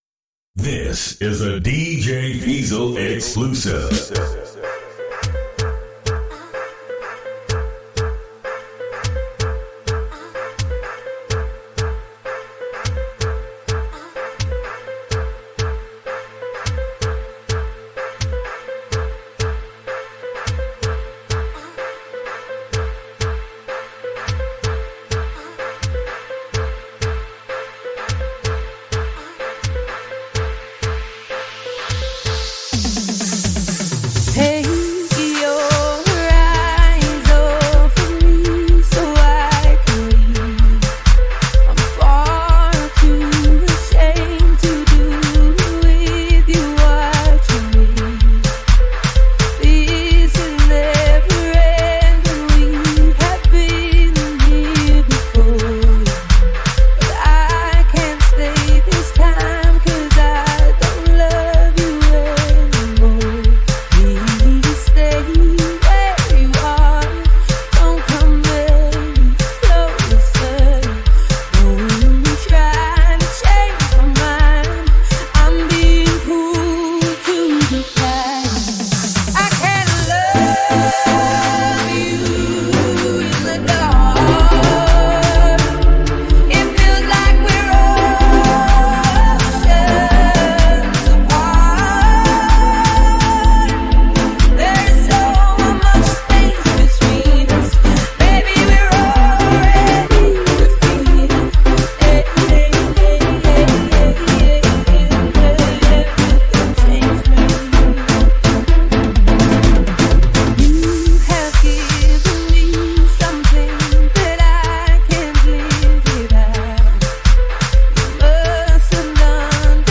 Get ready for a Gqom beats extravaganza like no other!